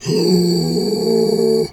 Animal_Impersonations
bear_pain_whimper_09.wav